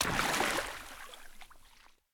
shallow-water-06.ogg